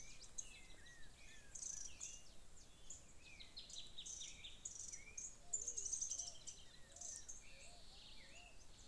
Arredio (Cranioleuca pyrrhophia)
Nome em Inglês: Stripe-crowned Spinetail
Condição: Selvagem
Certeza: Observado, Gravado Vocal